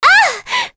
Aisha-Vox_Damage_01.wav